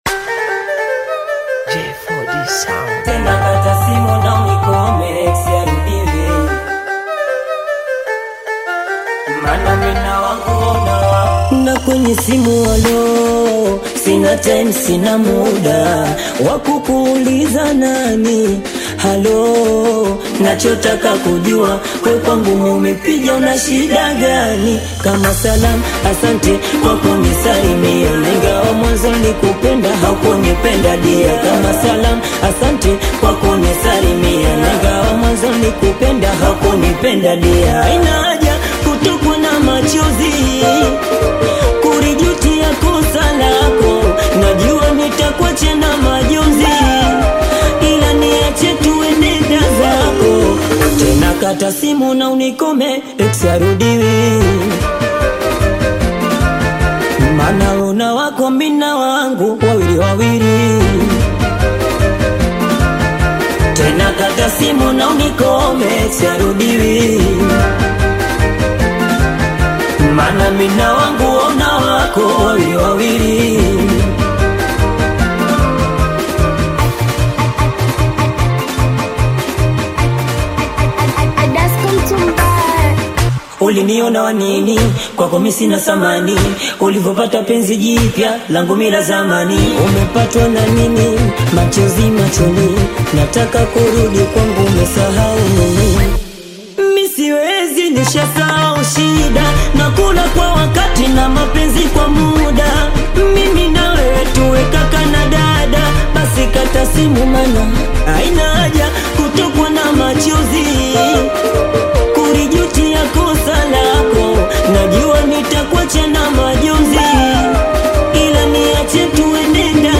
Singeli You may also like